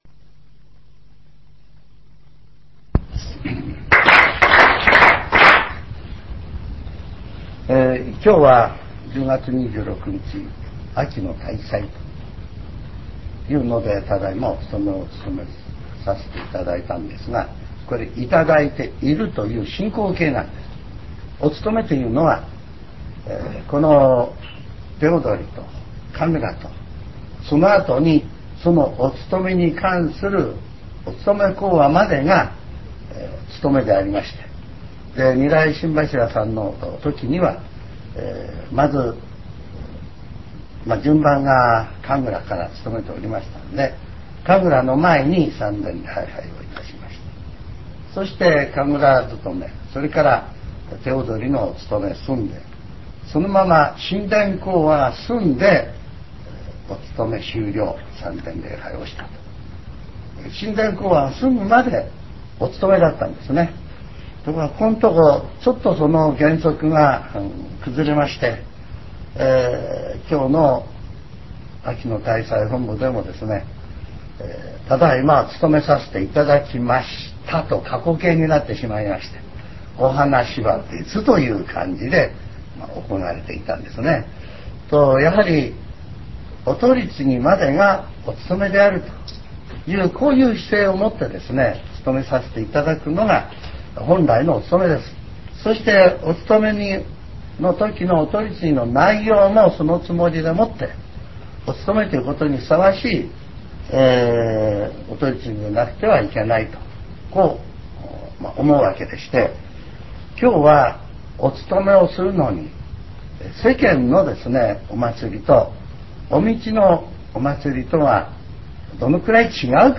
全70曲中2曲目 ジャンル: Speech